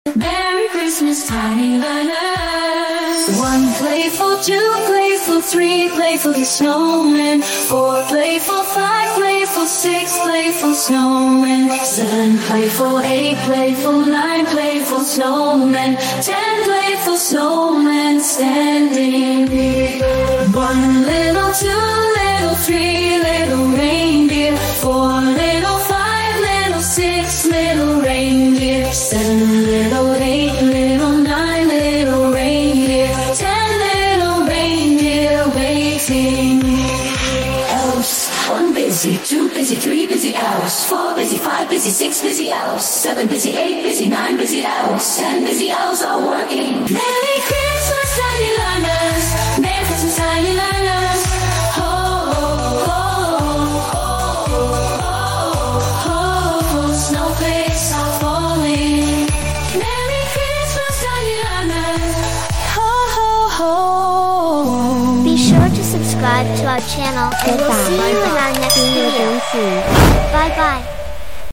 Today, we have a Christmas counting song.